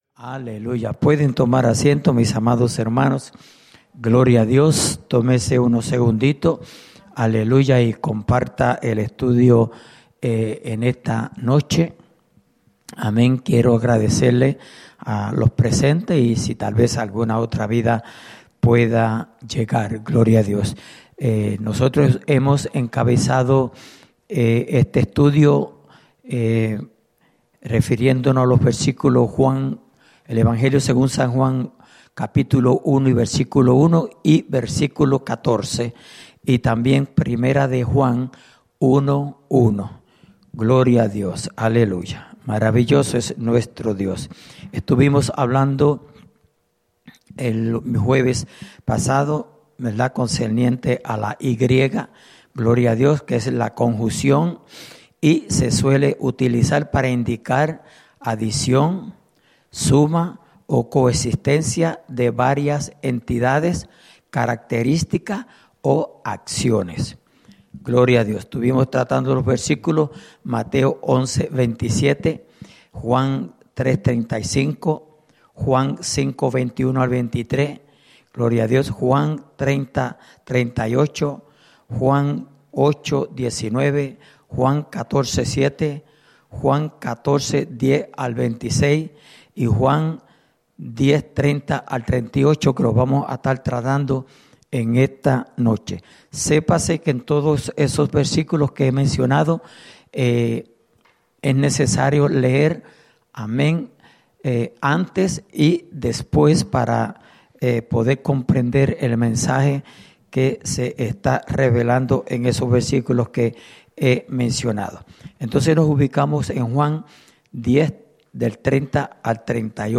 Estudio Bíblico: Padre Y Hijo (2.ª Parte)